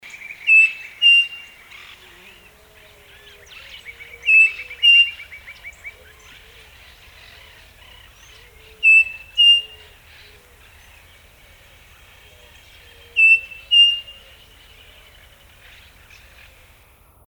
Saci (Tapera naevia)
Nome em Inglês: Striped Cuckoo
Fase da vida: Adulto
Localidade ou área protegida: Reserva Privada El Potrero de San Lorenzo, Gualeguaychú
Condição: Selvagem
Certeza: Observado, Gravado Vocal